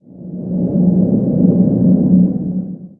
wind3.wav